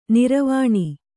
♪ niravāṇi